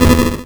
Shark_Death.wav